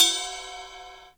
70TECHRIDE-R.wav